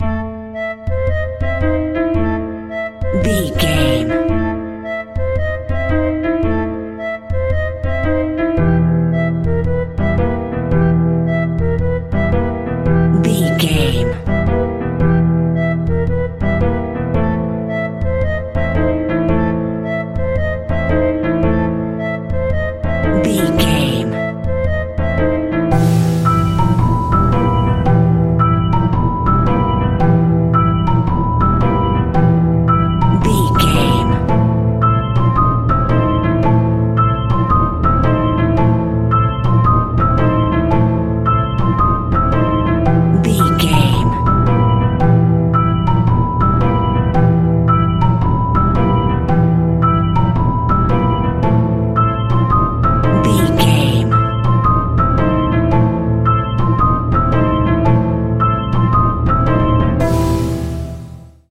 royalty free music
Aeolian/Minor
scary
ominous
fun
haunting
eerie
flute
piano
electric organ
drums
percussion
spooky
horror music